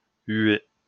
Huest (French pronunciation: [y'e]